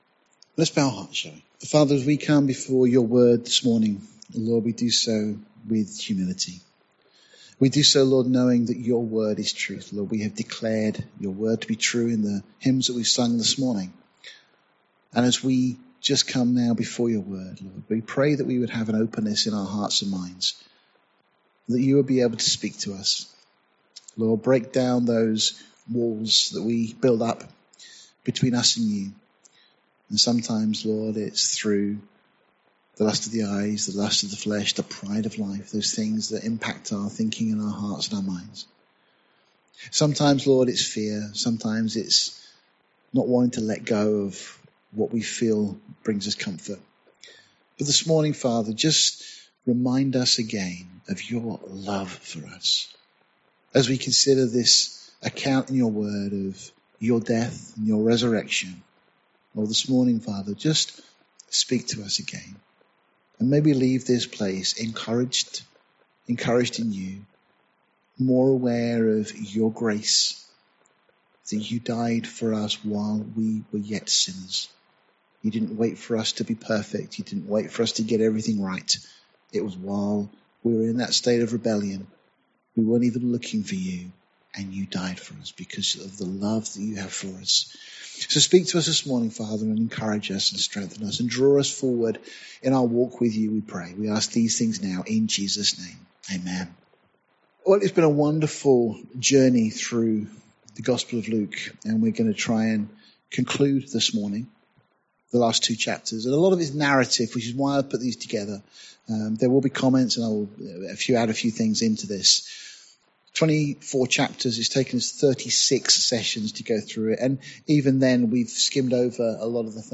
Series: Passion Week Series , Sunday morning studies Tagged with Passion Week , resurrection , verse by verse